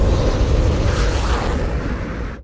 move3.ogg